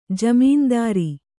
♪ jamīndāri